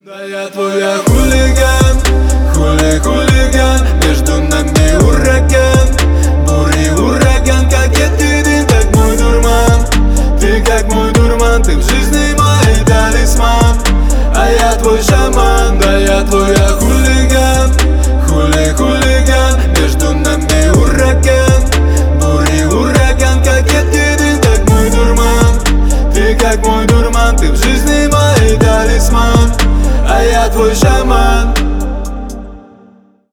• Качество: 320 kbps, Stereo
Поп Музыка
грустные